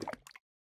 drip_lava5.ogg